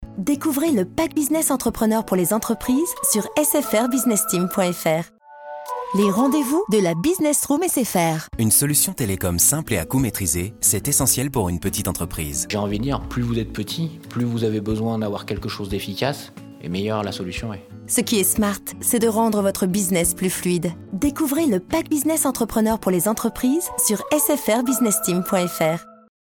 Voix-off féminine pro, voix fraiches, mutines, complices ou chantantes.
SFR-Pack-Business-corporate-souriante.mp3